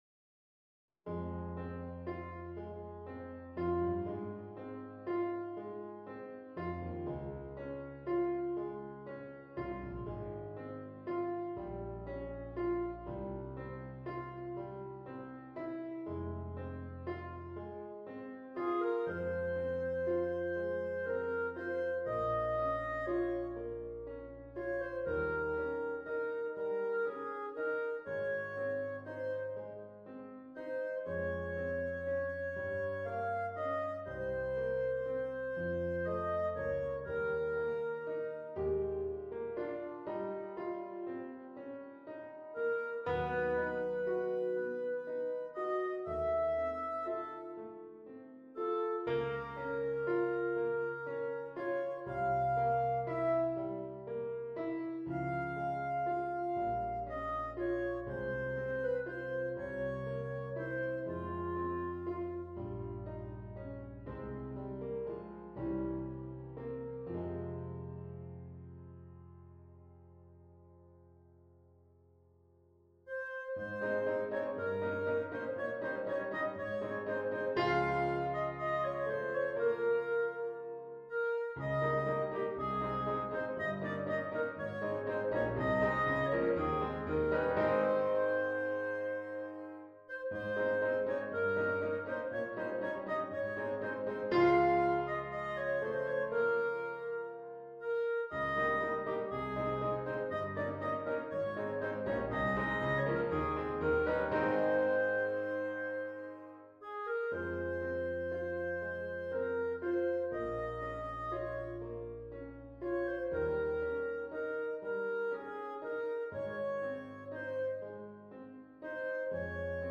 This file contains a performance, an accompaniment, and the Bb clarinet sheet music for Schubert's Lieder An den Mond.